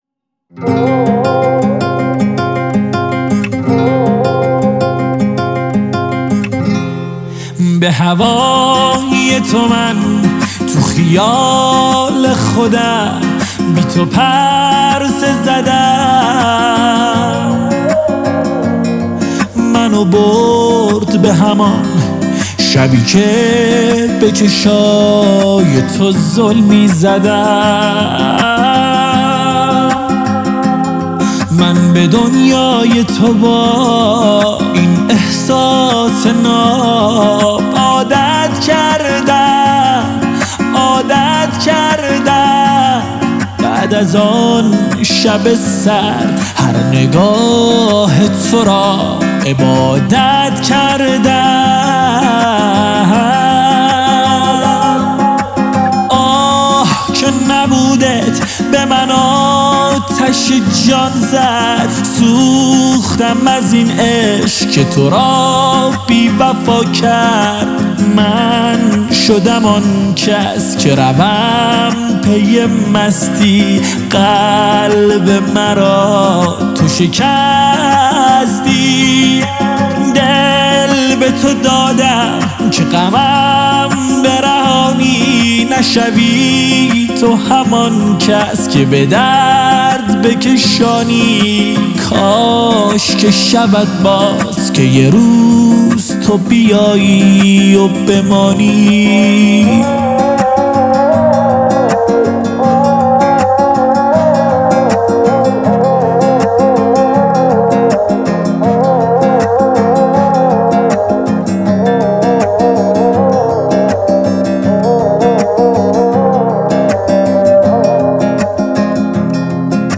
موزیک عاشقانه